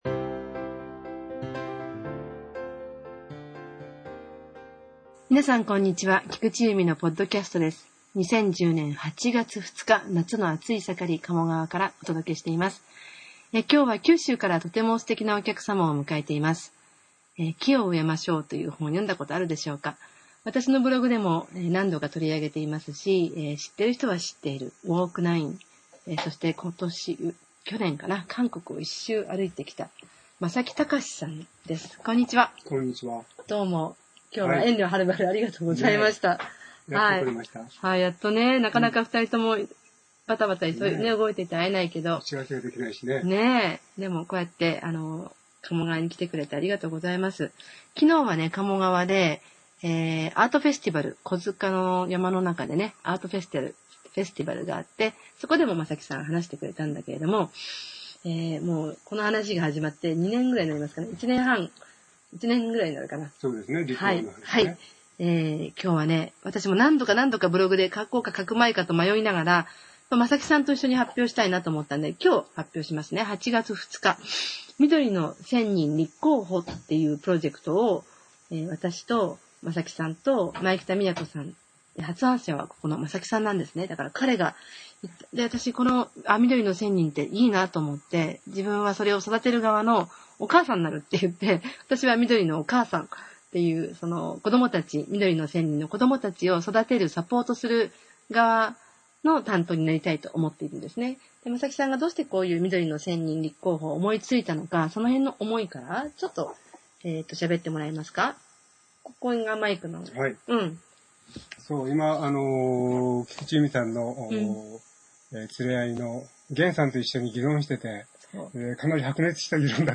インタビューしました。